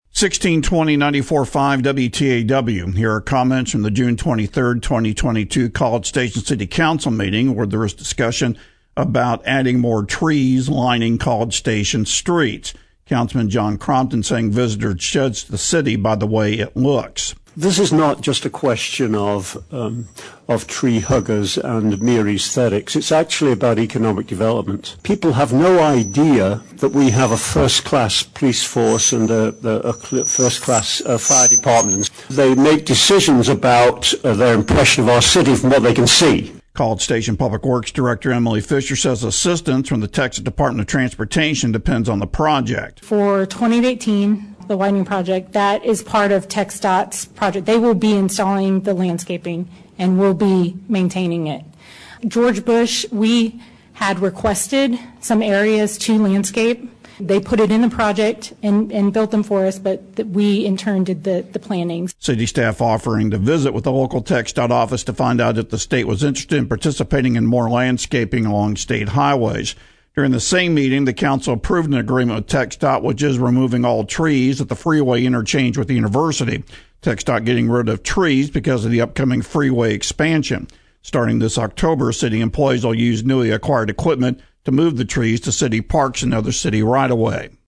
Click HERE to read and download presentation materials from the June 23, 2022 College Station city council meeting.